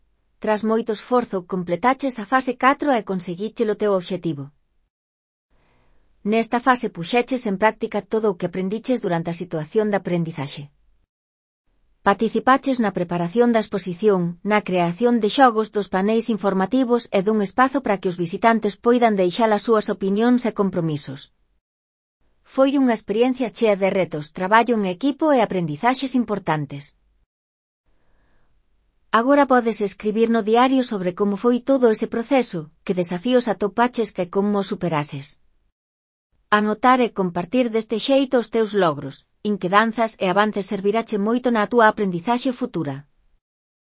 Elaboración propia (proxecto cREAgal) con apoio de IA, voz sintética xerada co modelo Celtia. Diario4 (CC BY-NC-SA 4.0)